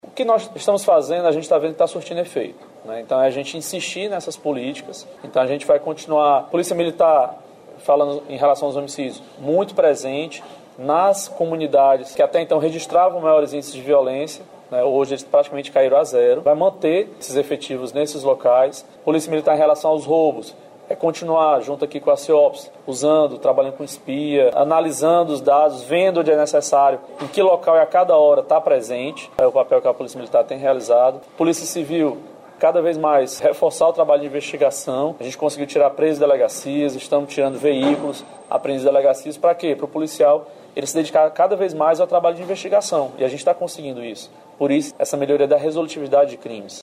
O secretário André Costa destacou como as estratégias e medidas adotadas pela Segurança Pública cearense têm impactado na melhoria dos índices.